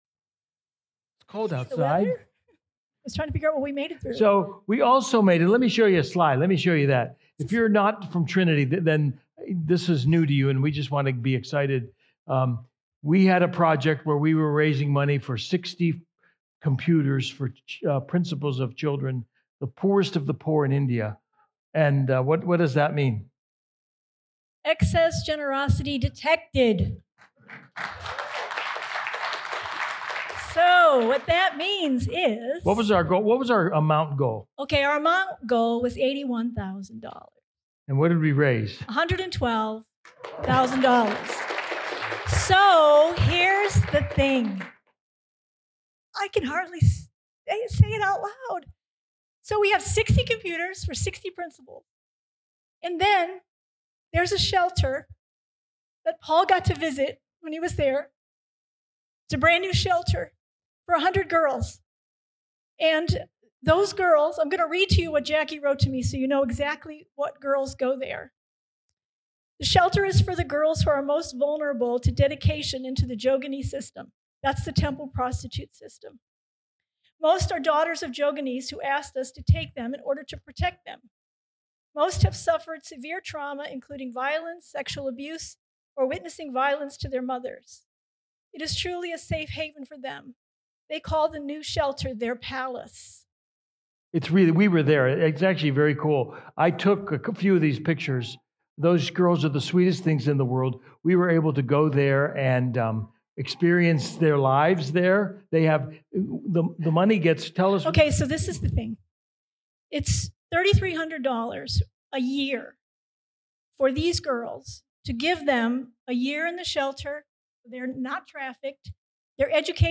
Christmas Eve at Trinity